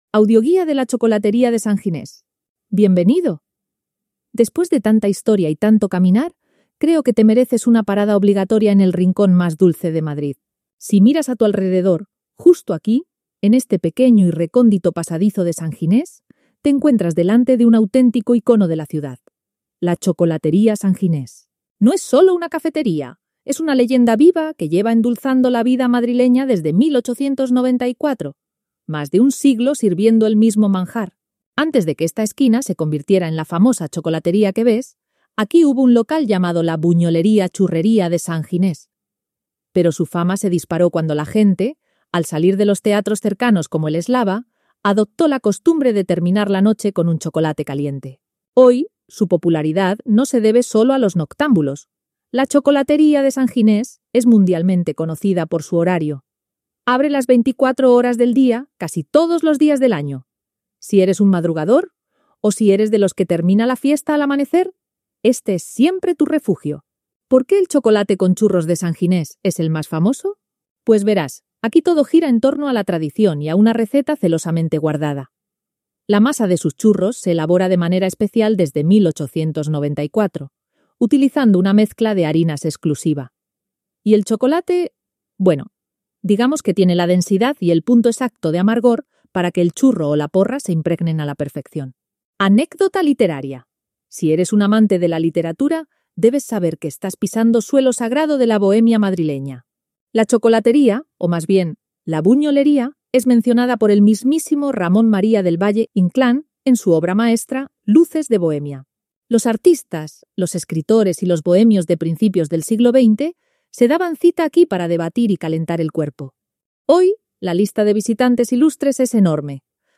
Audioguía: Chocolatería de San Ginés
AUDIOGUIA-DE-LA-CHOCOLATERIA-DE-SAN-GINES.mp3